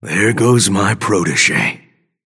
Grey Talon voice line (Shiv unkillable) - There goes my protegé.